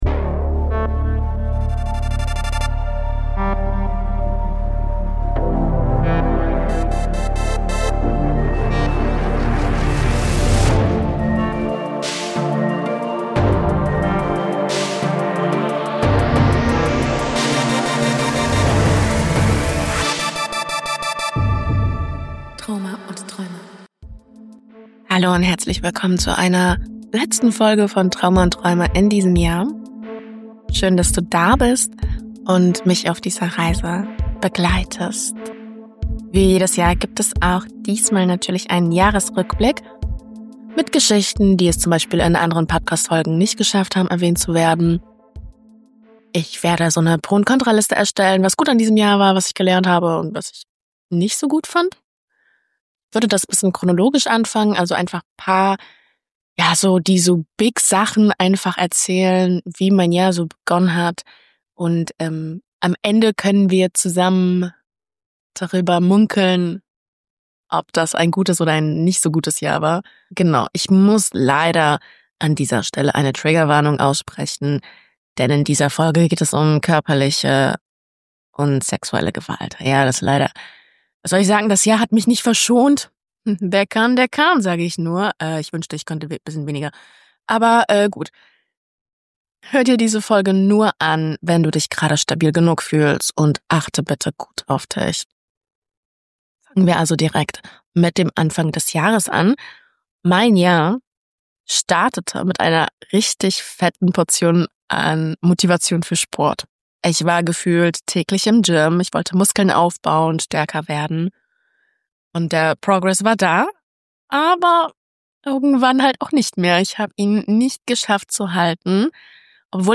Ein ehrlicher Podcast über Trauma, Bindung, Heilung und das Chaos dazwischen. Persönlich, ungeschönt, manchmal wütend, manchmal zart.